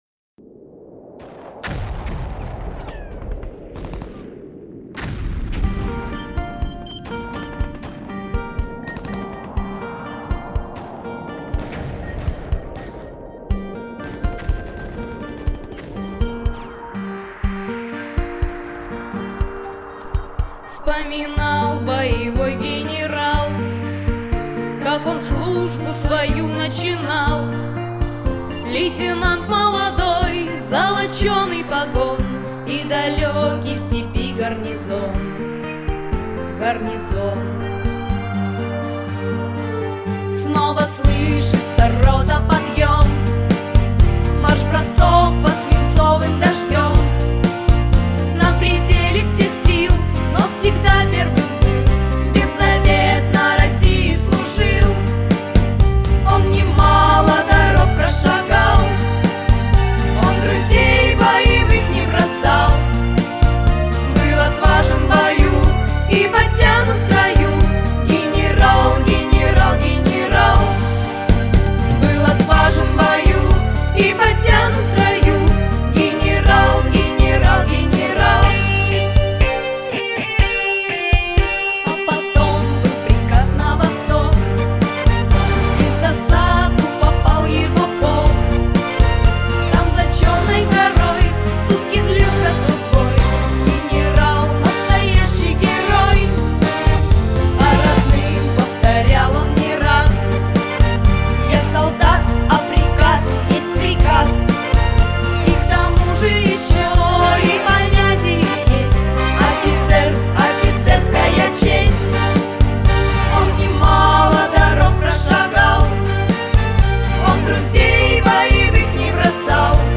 • Категория: Детские песни
Армейская патриотическая музыка на 23 Февраля.